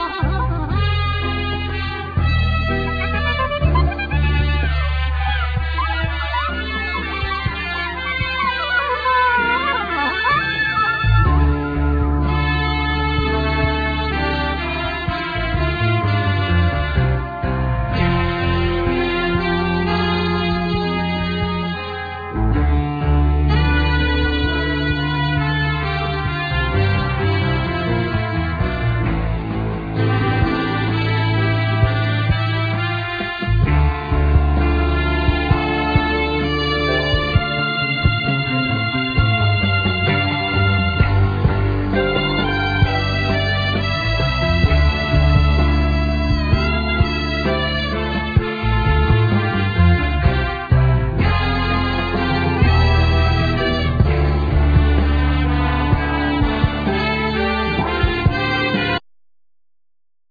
Alto+Soprano sax
Piano,Keyboards
Guitar
Bass
Trumpet
Trombone
Drums
Vocals